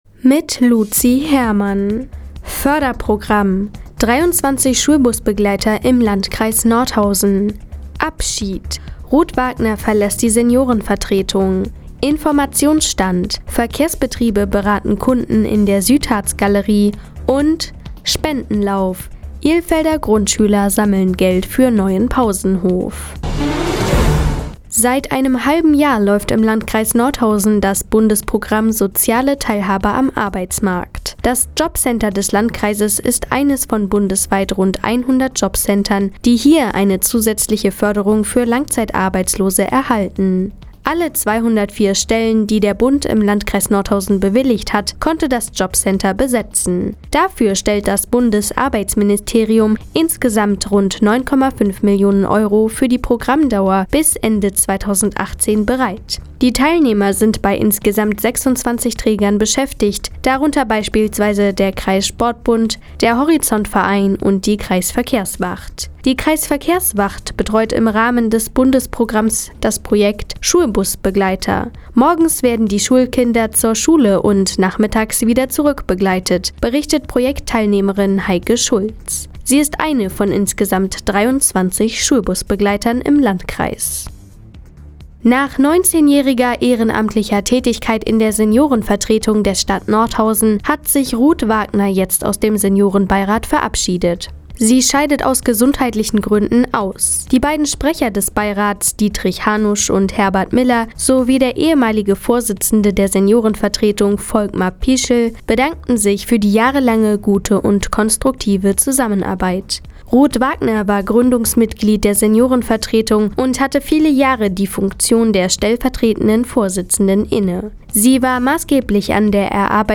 Mo, 17:00 Uhr 02.05.2016 Neues von Radio ENNO „Fazit des Tages“ Seit Jahren kooperieren die Nordthüringer Online-Zeitungen und das Nordhäuser Bürgerradio ENNO. Die tägliche Nachrichtensendung ist jetzt hier zu hören.